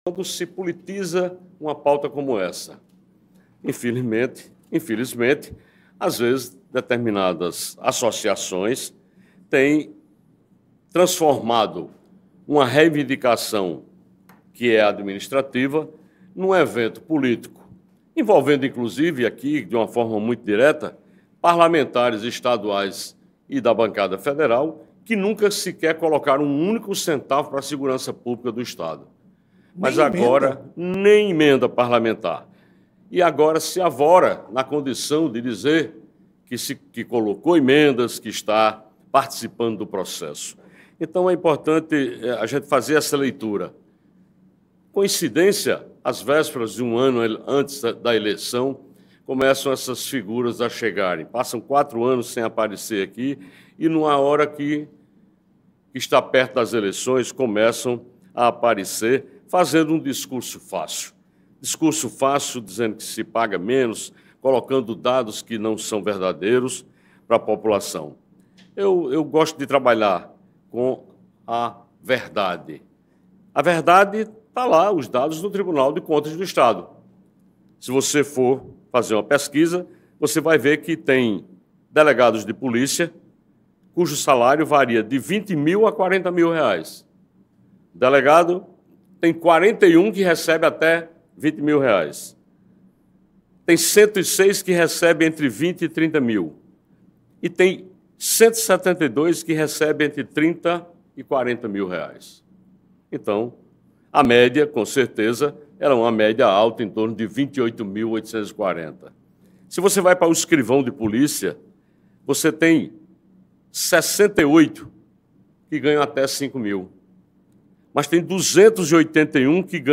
As afirmações foram feitas durante o Programa Conversa com o Governador, transmitido pela Rádio Tabajara nesta segunda-feira (17).